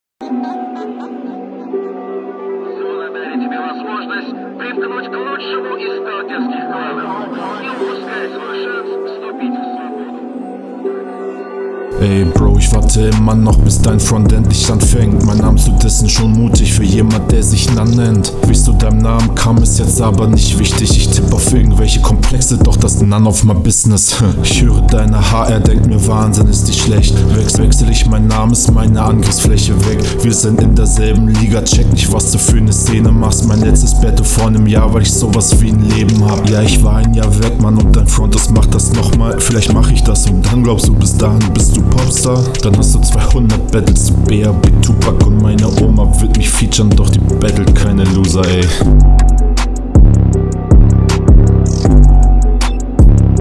Klingt alles irgendwie ein bisschen monoton.